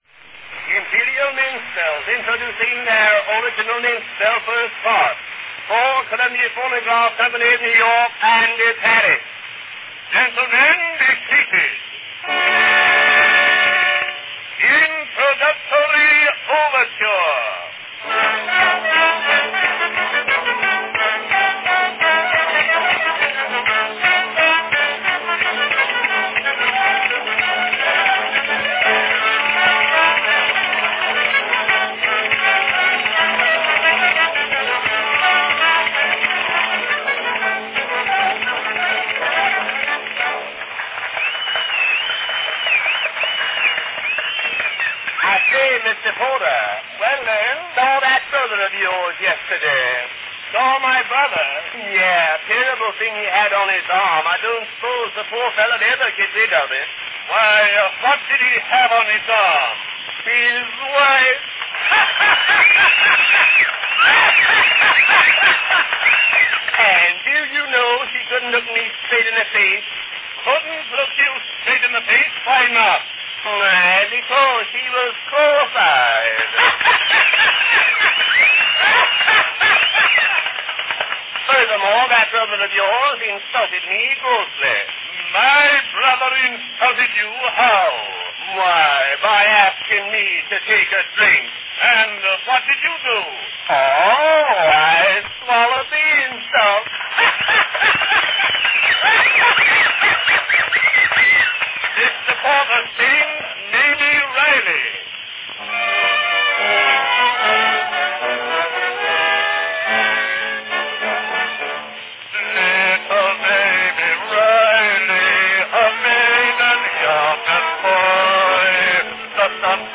Enjoy a freewheeling minstrel recording from 1898 by the Imperial Minstrels.
Category Minstrels
Performed by Len Spencer & Steve Porter
With wild jokes and offbeat humor, these wax cylinder recordings in the style of minstrel shows of the day are often great fun to listen to.
Note the profuse and forced belly laughs by Spencer!